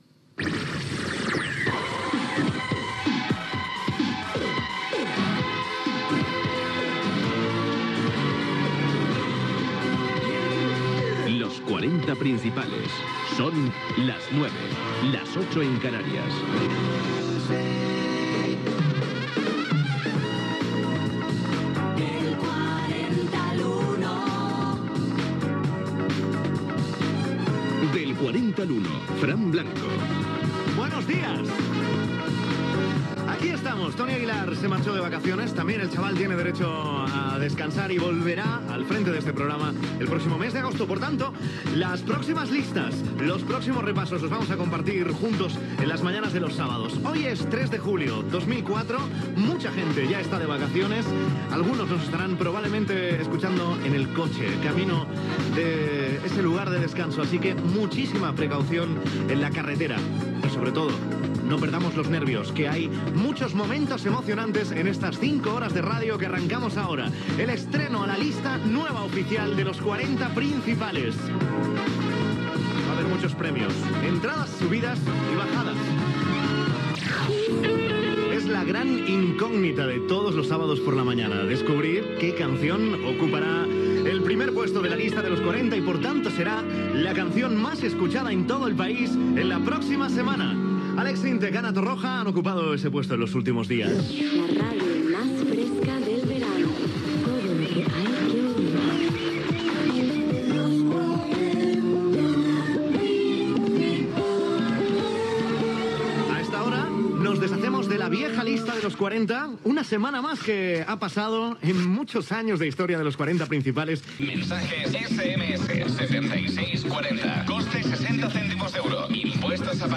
Hora, identificació de l'emissora i del programa, presentació, invitació a la participació
Musical
FM